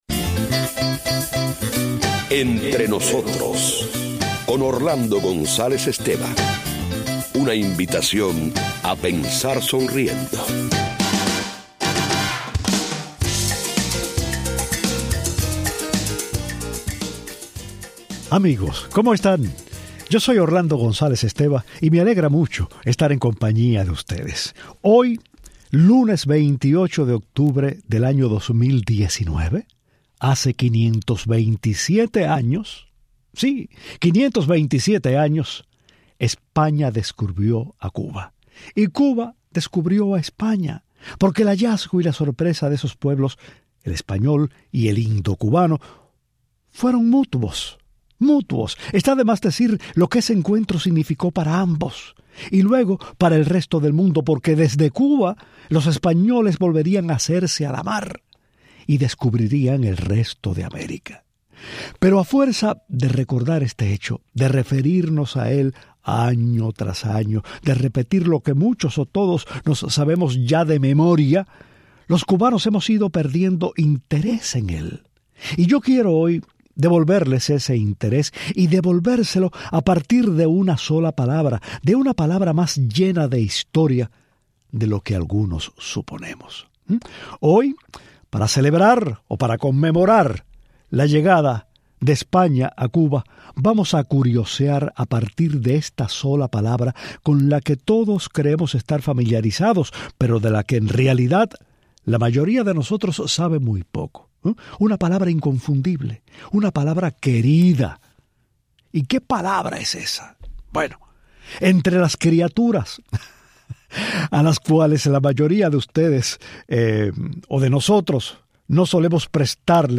Hoy, 28 de octubre, se cumplen 527 años de la llegada de Cristóbal Colón a Cuba. Una bella conferencia de José Juan Arrom recuerda la lucha de esta palabra por imponerse a los otros nombres que se intentó darle a la isla: Juana, Isabela, Fernandin